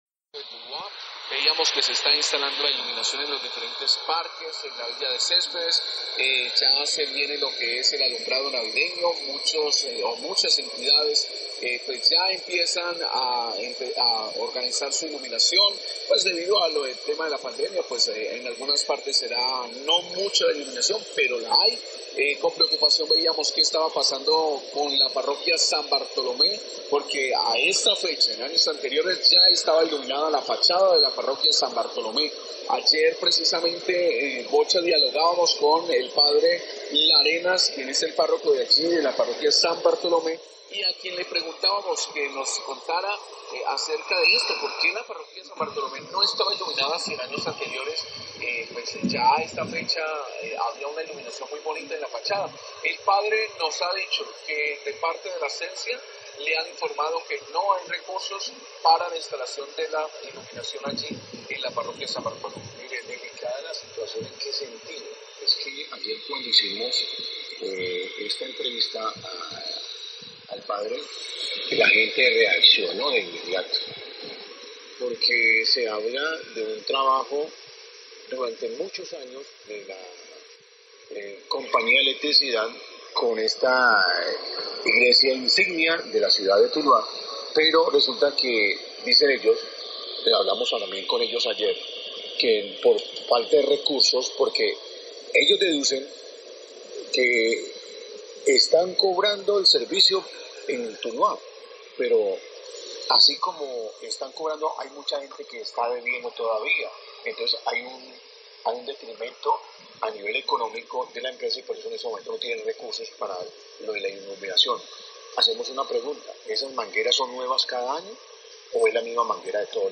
Radio
Periodistas comentan que Celsia no apoyará el alumbrado navideño de la Iglesia de San Bartolomé ante la falta de recursos por la pandemia por coronavirus.